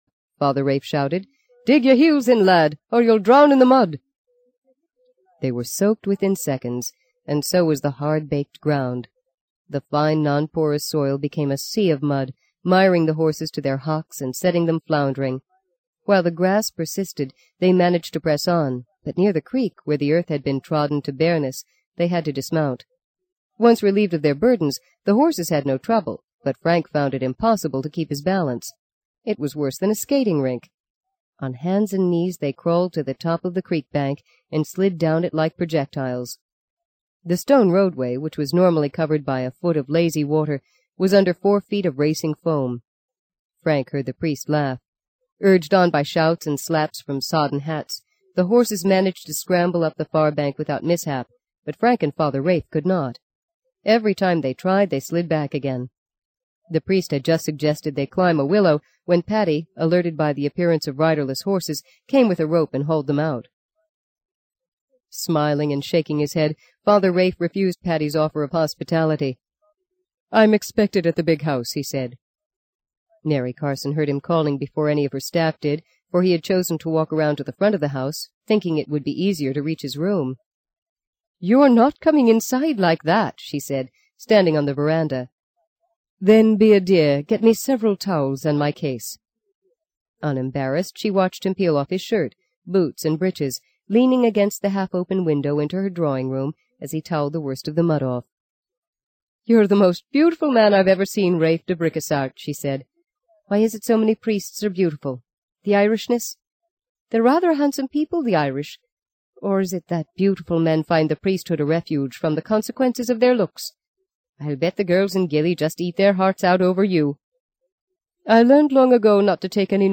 在线英语听力室【荆棘鸟】第四章 12的听力文件下载,荆棘鸟—双语有声读物—听力教程—英语听力—在线英语听力室